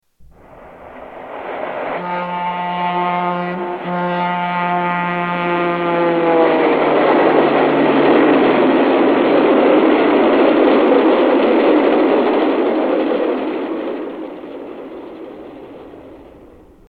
Diesel with Horn